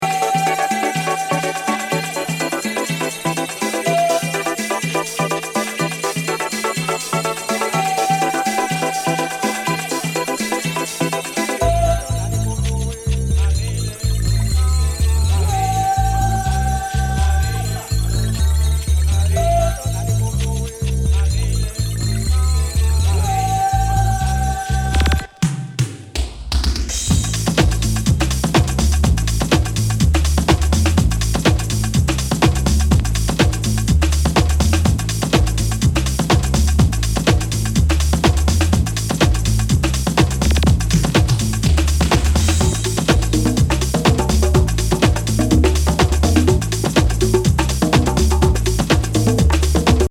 プログレッシヴ　ハウスのフロア・キラー・チューンばかりを集めた激カルト・
コンピ！ユーロ感、UK Rockin’な質感をダンストラックに散りばめた